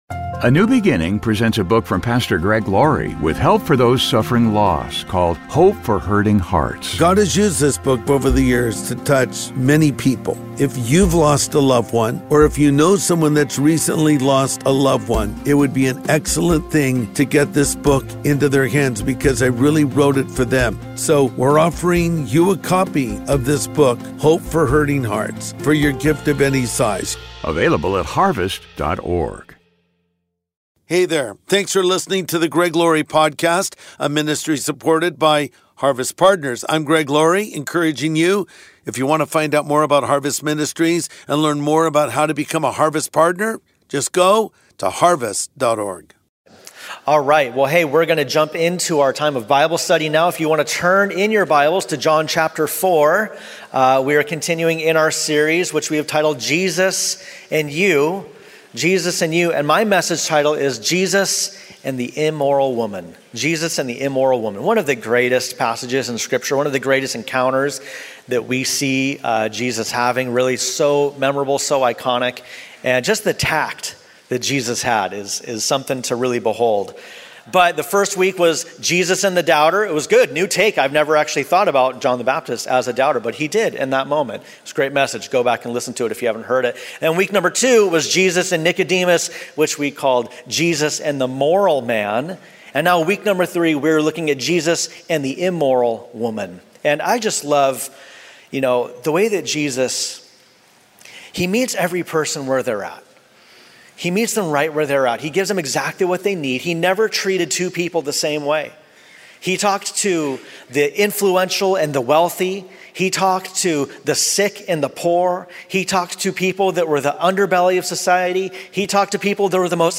Jesus and the Immoral Woman | Sunday Message Podcast with Greg Laurie
Jesus and the Immoral Woman | Sunday Message